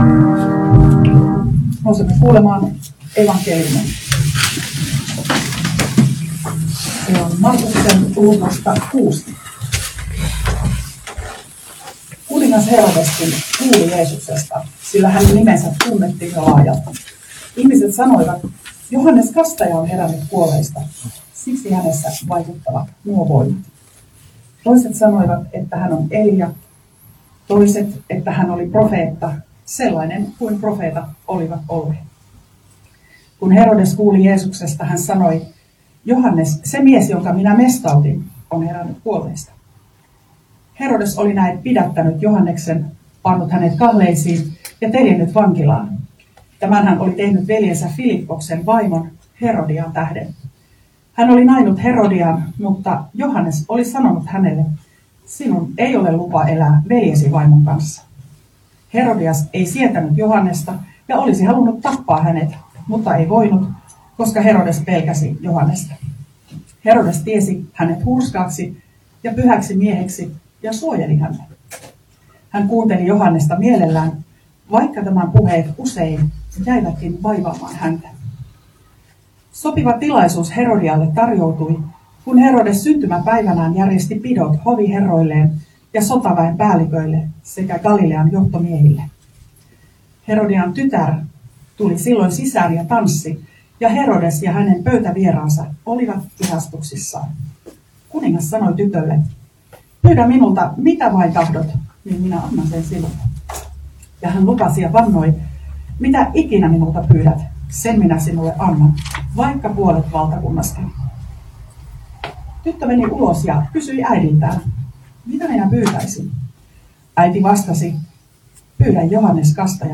Kannuksessa juhannuspäivänä